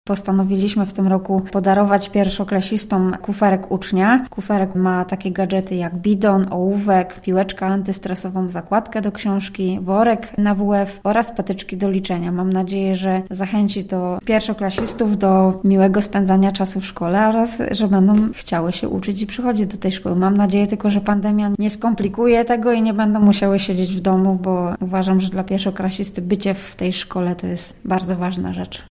tłumaczy Anna Fabiś, zastępca burmistrza Świebodzina: